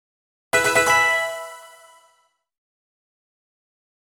levelup.mp3